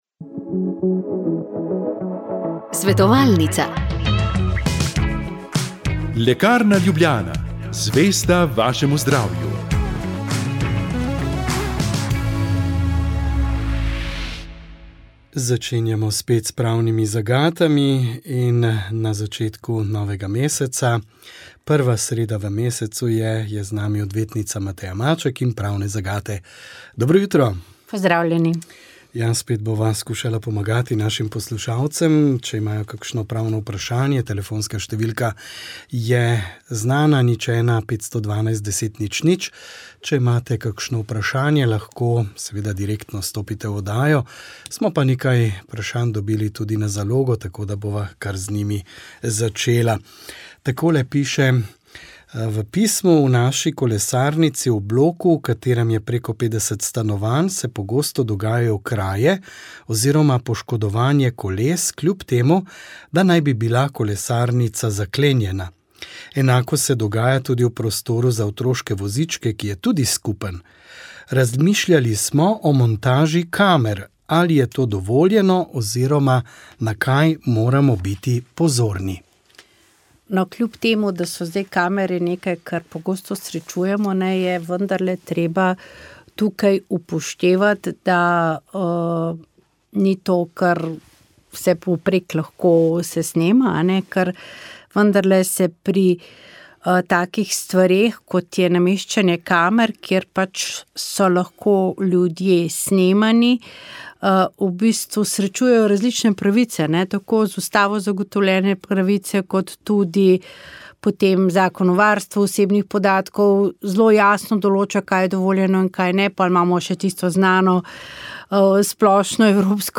V 16. epizodi podkasta RAST smo gostili dr. Jožeta Podgorška, predsednika Kmetijsko gozdarske zbornice Slovenije. Prisluhnite, kako vodenje te največje kmečke stanovske organizacije vidi po letu dni »zelo razburkane plovbe« in kakšni so izzivi, ki jih napovedujejo pretresi na kmetijskem področju v prihodnjem letu.